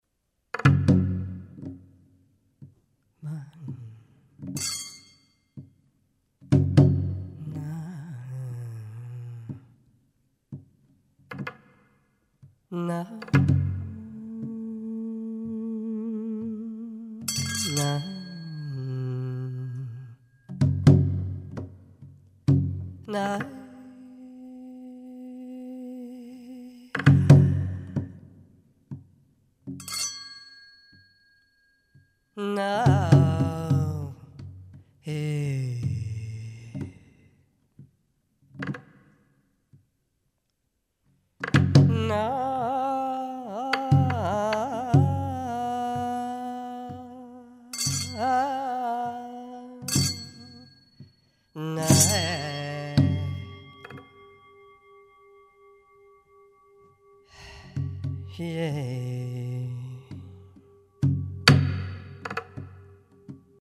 voice
percussion